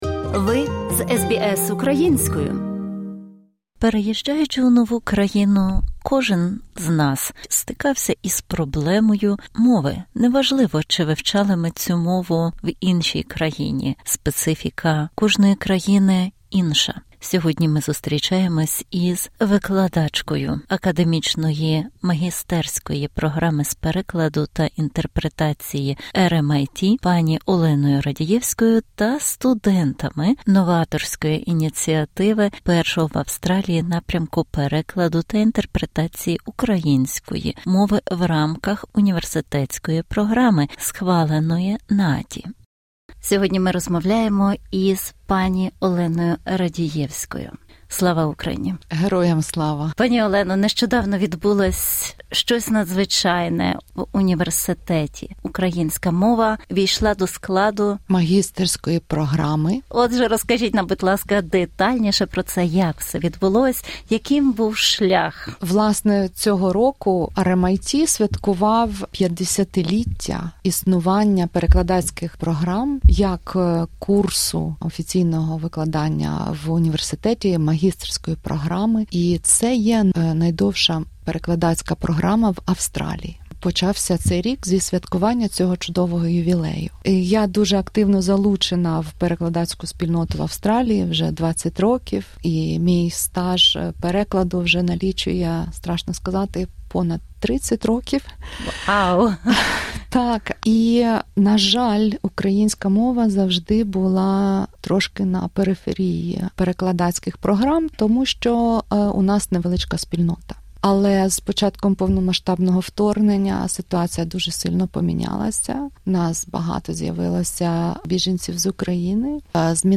SBS Українською View Podcast Series Follow and Subscribe Apple Podcasts YouTube Spotify Download (14.94MB) Download the SBS Audio app Available on iOS and Android Частина перша.